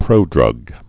(prōdrŭg)